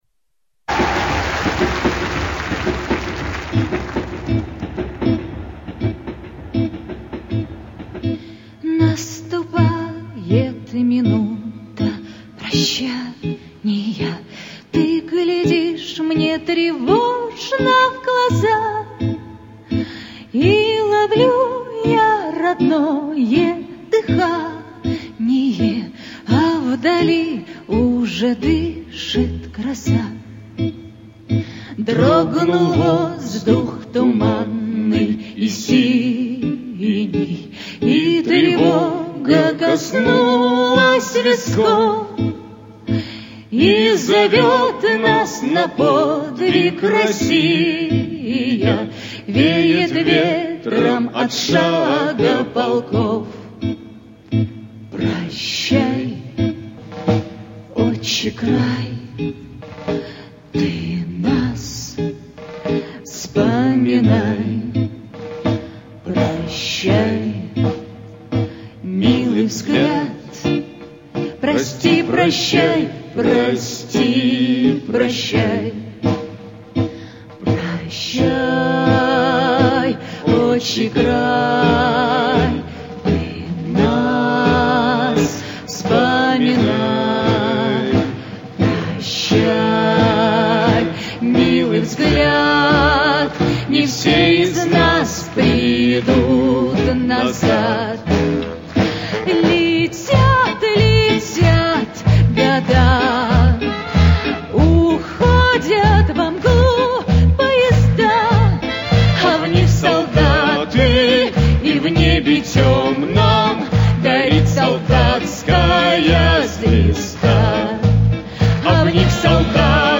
Известнейший военный марш, исполненный как лирическая песня.